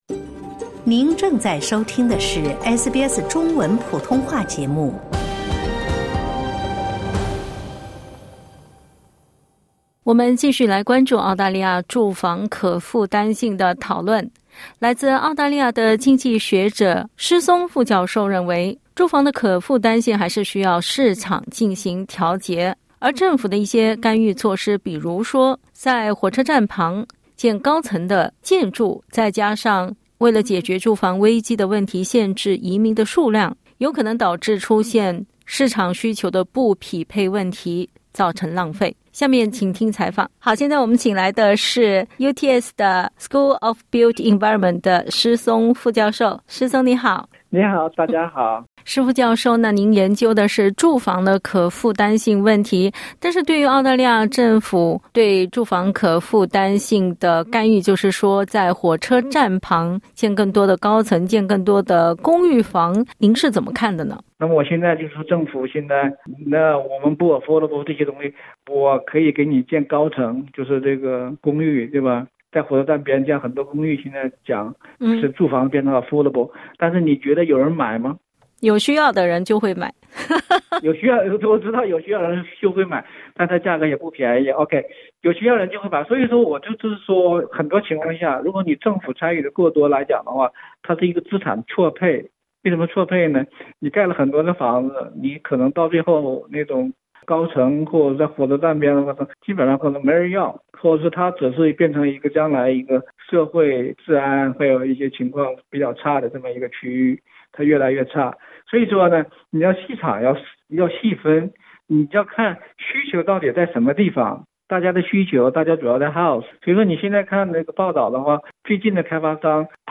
（点击音频收听详细采访）